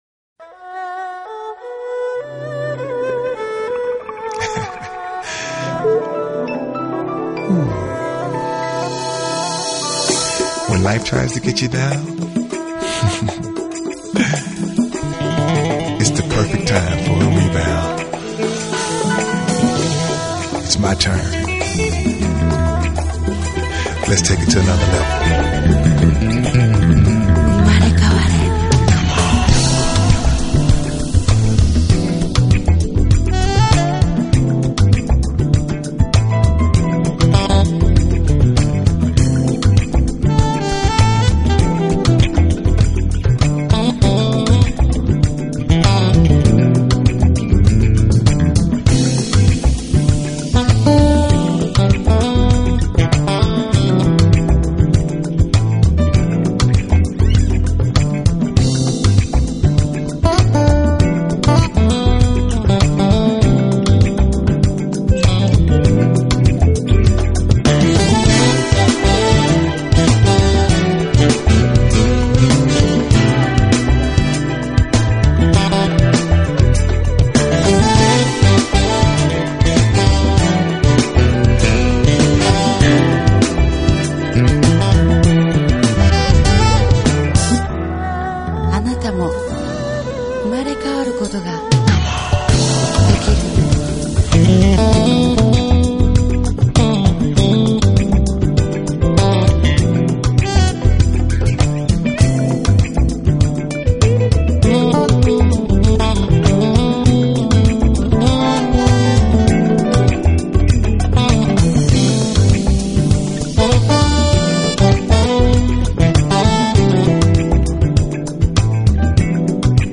爵士，加入了不少R&B和流行曲风，还有翻唱的老流行歌曲。